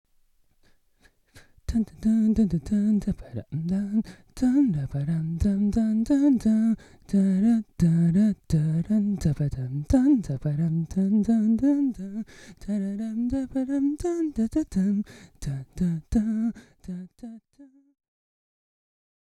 平日夜に自宅で一発どりしたので恐ろしく音圧は低いですが、それでもしっかり拾っています。
ちなみに、コンプレッサーやイコライザはいじっていません！リバーブもコーラスも足してないです！
※同じく無加工の一発どりです
▼コーラス(LINE)
アンプは噛ませずに相棒のAKG D5を繋いで歌うと音質はこんな感じでした。
近所を気にしながら小声でうたってもしっかりと音を拾ってくれています！
vocal_cho_line.m4a